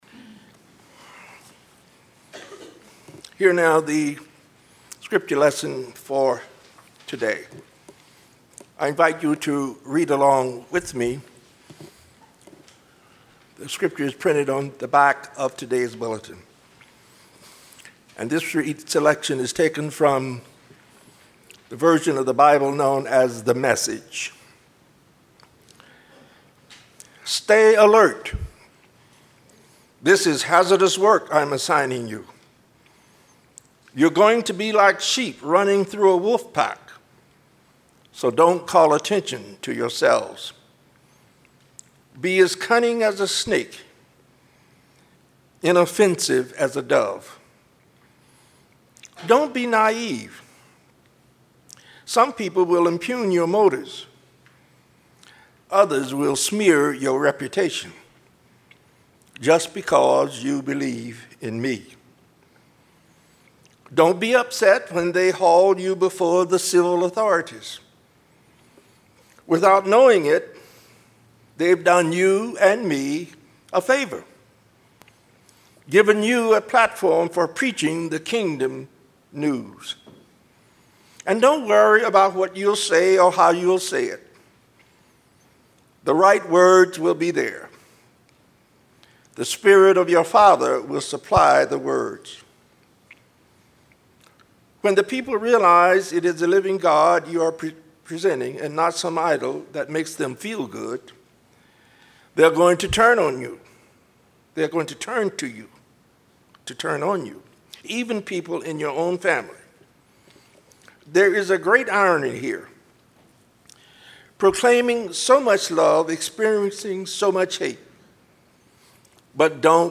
audio of sermon
Lakewood United Methodist Church, 10:25 am